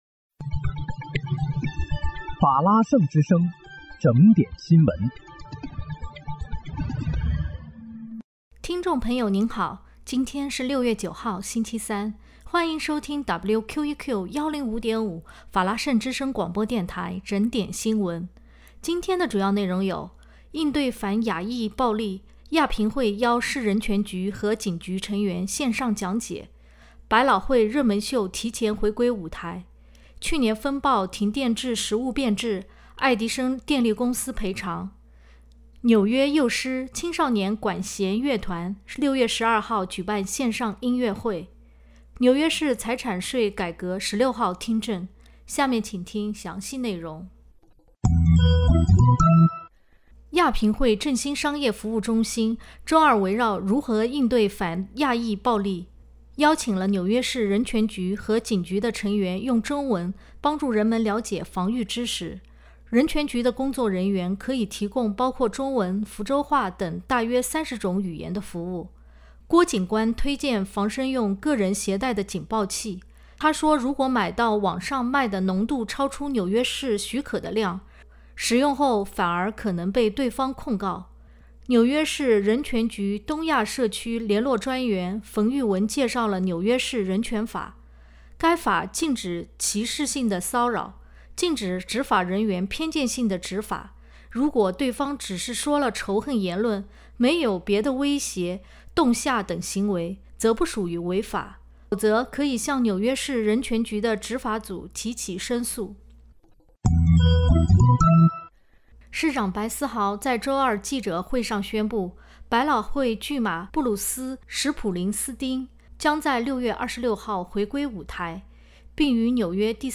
6月9日（星期三）纽约整点新闻
听众朋友您好！今天是6月9号，星期三，欢迎收听WQEQ105.5法拉盛之声广播电台整点新闻。